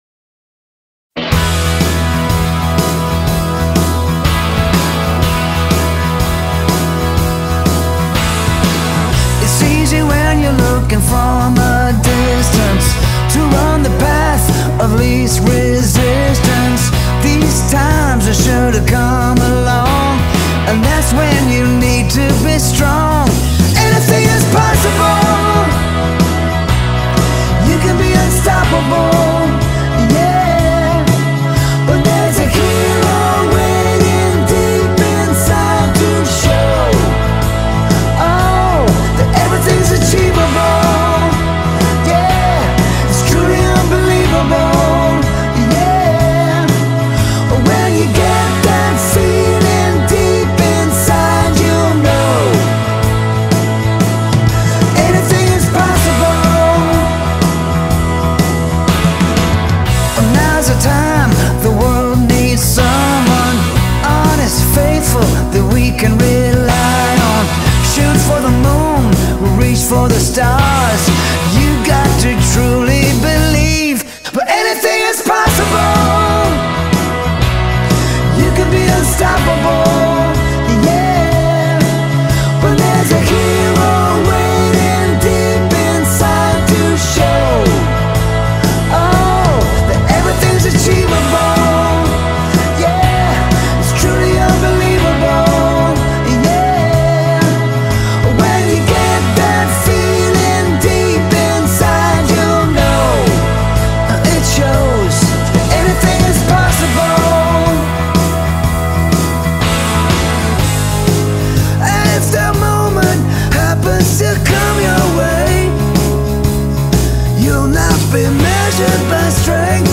Big heart. Big hook.